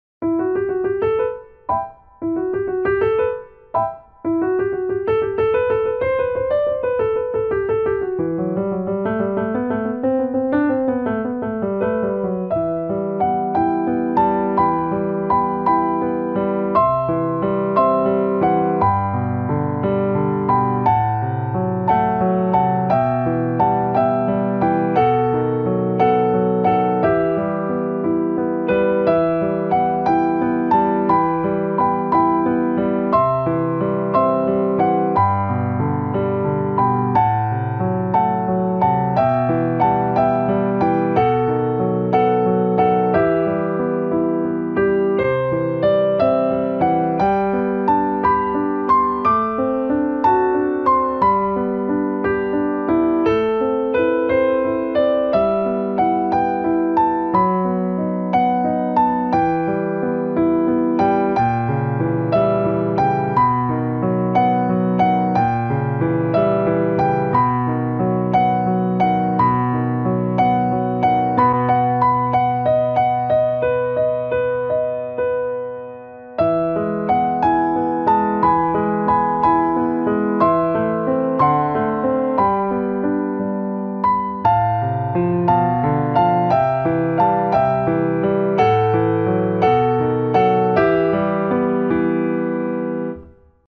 Vltava (Má vlast) na klavír - Bedřich Smetana